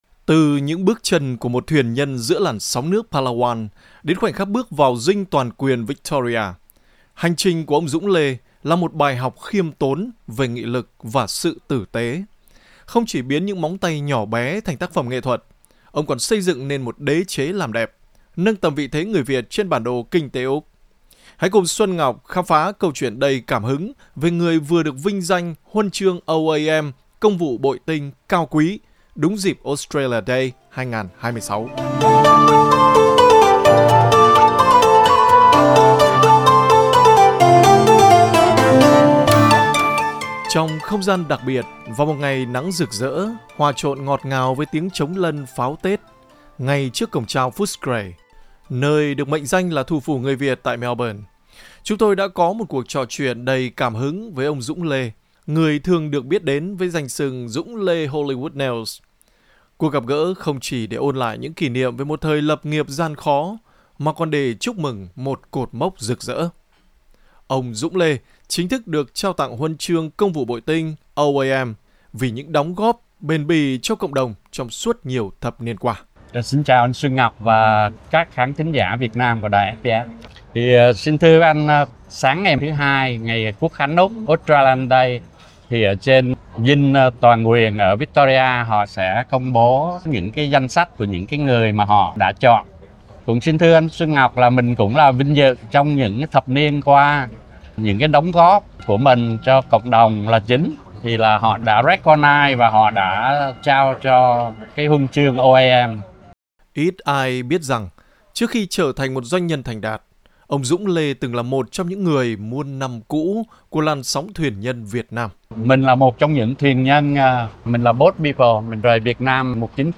Trong không gian đặc biệt vào một ngày nắng rực rỡ hòa trộn ngọt ngào với tiếng trống lân, pháo Tết, ngay trước cổng chào Footscray – nơi được mệnh danh là "thủ phủ" người Việt tại Melbourne, chúng tôi đã có một cuộc trò chuyện đầy cảm hứng